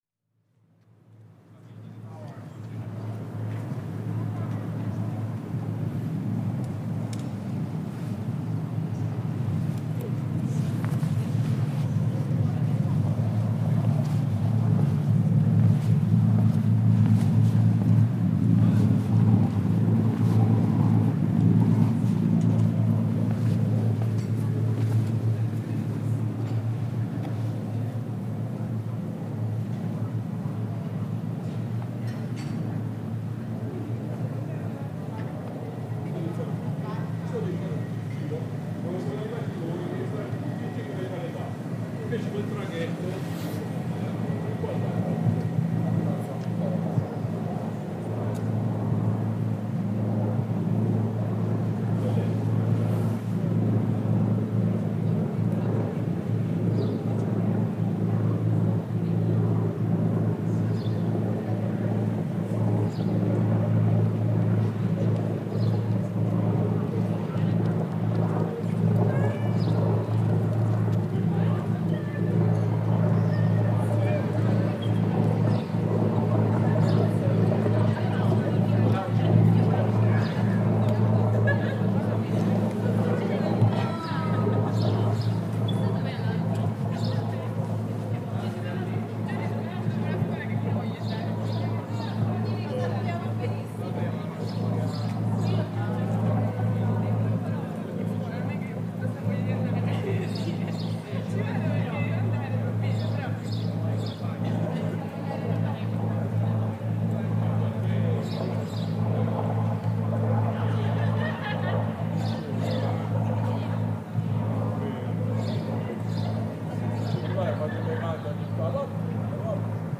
The port of Brindisi, end of the Appian Way at five in the afternoon, along the steps at the foot of the Roman columns.
Two stretches of still and silent sea, the engine of the boat that connects the shores of the port, the bar and its cheerful customers, birds singing in spring, a sudden plane that takes flight from the nearby airport.